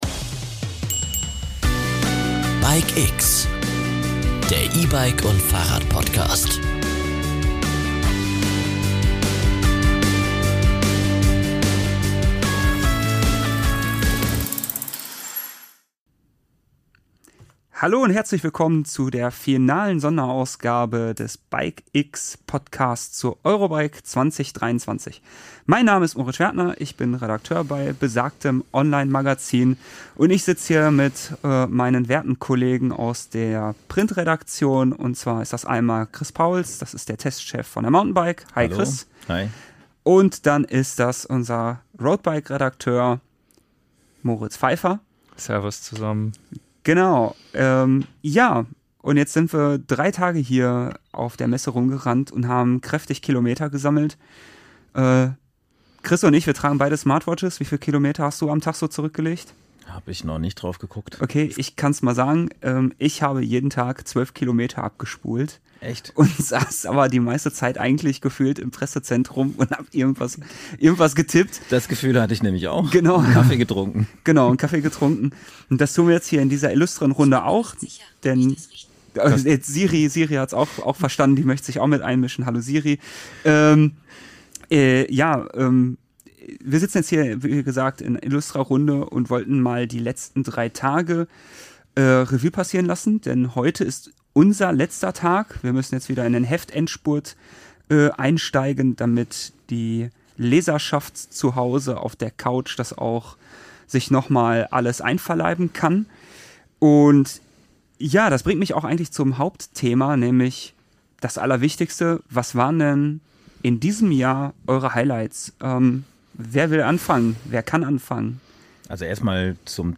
In vier Sonderfolgen beleuchtet die BikeX-Redaktion im Podcast-Format die Eurobike-Messe 2023.